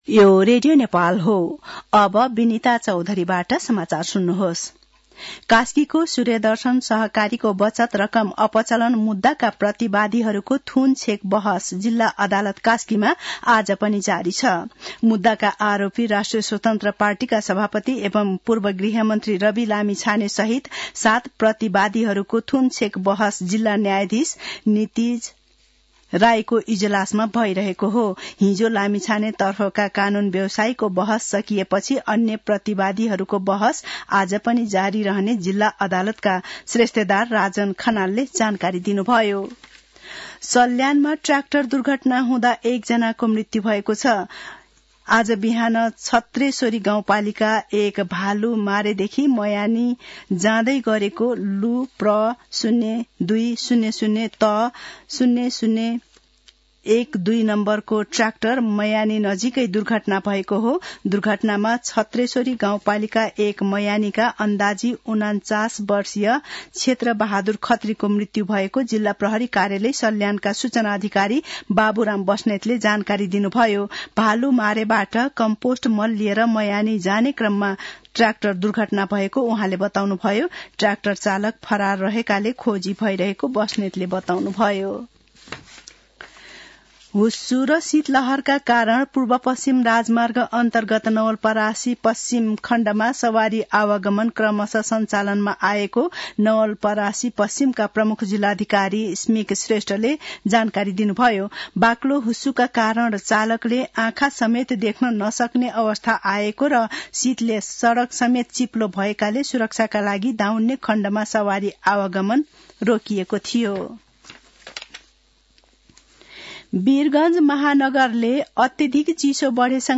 मध्यान्ह १२ बजेको नेपाली समाचार : २५ पुष , २०८१